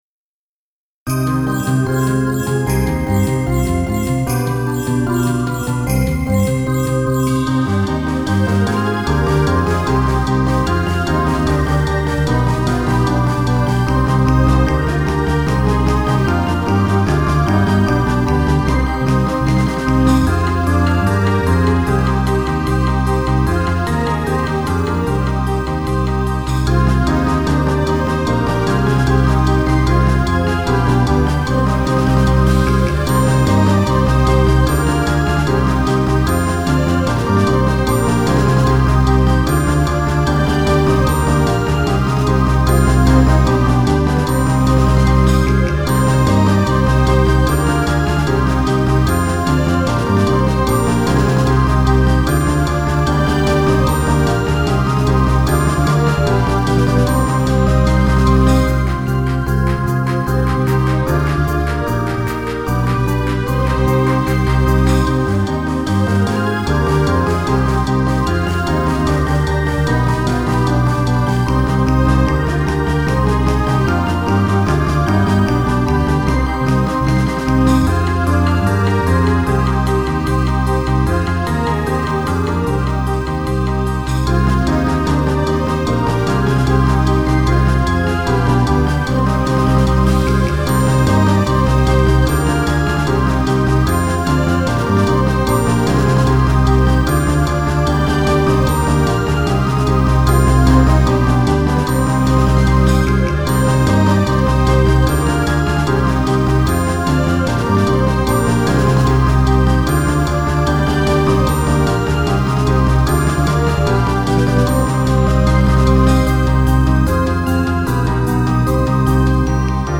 ハ短調